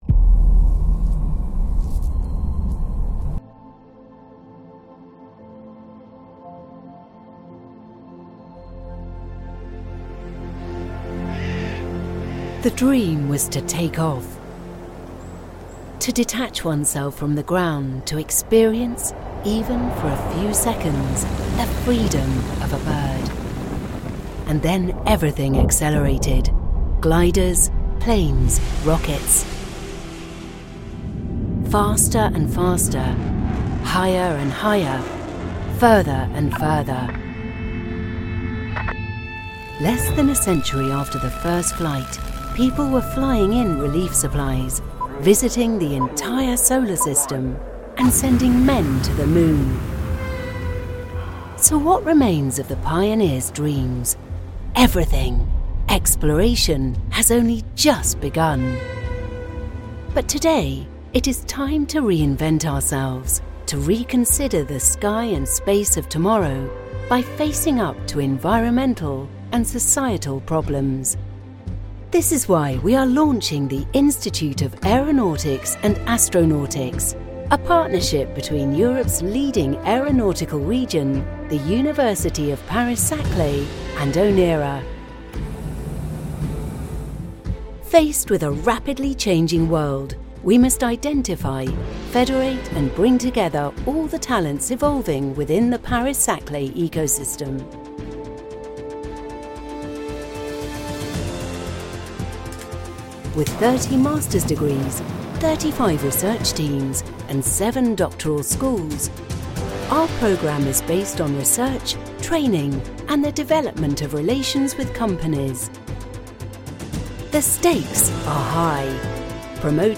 Anglais (Britannique)
Commerciale, Chaude, Polyvalente, Amicale, Corporative
Corporate
Vidéo explicative